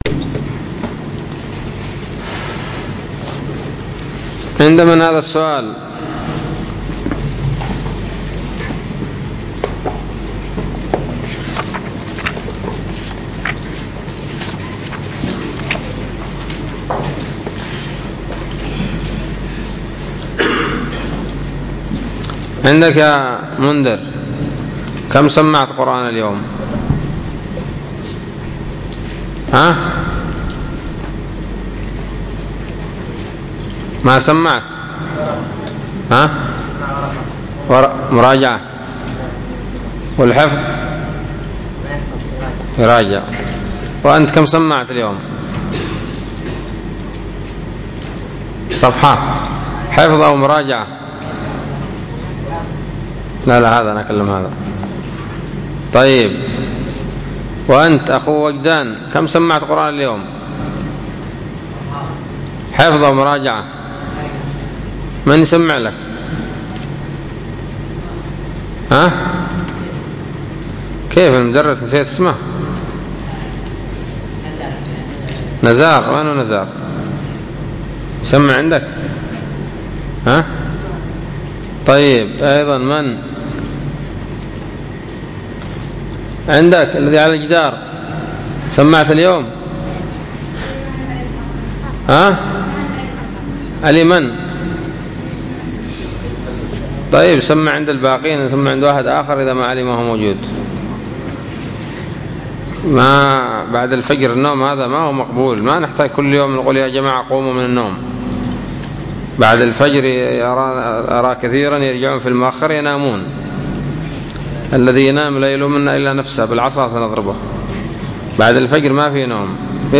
الدرس السادس والثلاثون من شرح العقيدة الواسطية